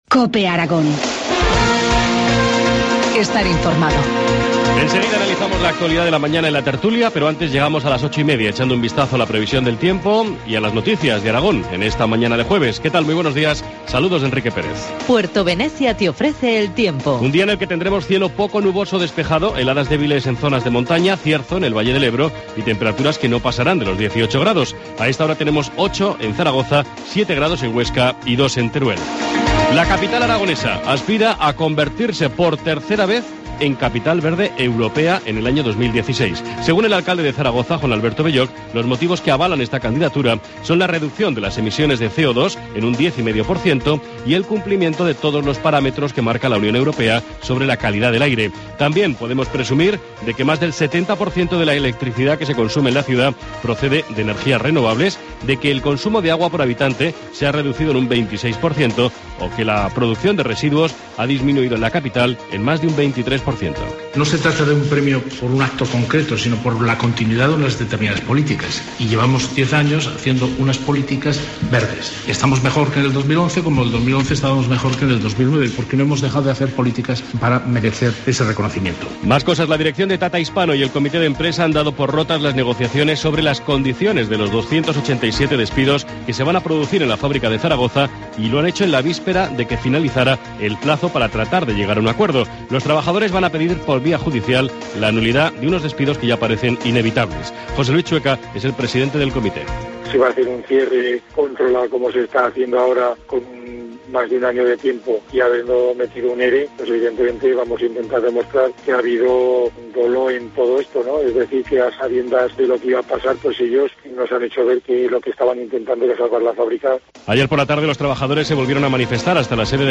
Informativo matinal, jueves 31 de octubre, 8.25 horas